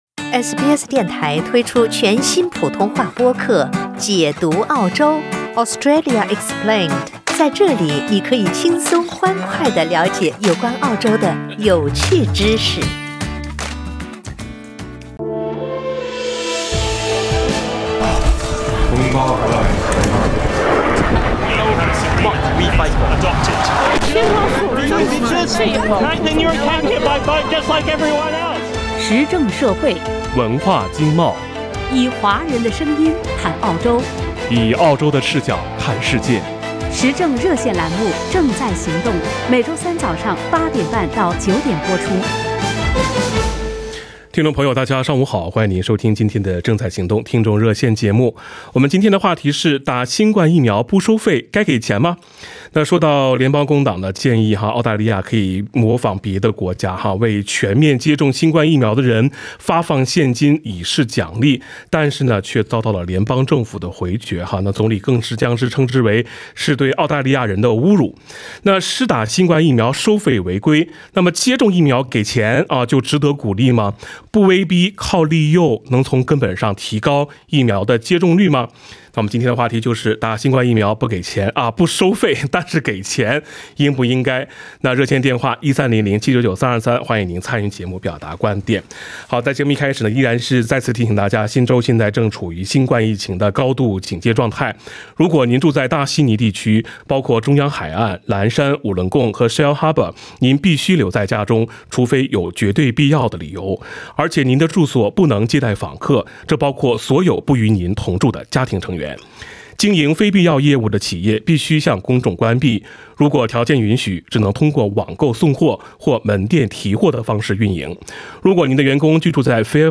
(点击封面图片，收听热线回放）